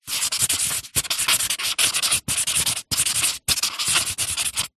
Звуки маркера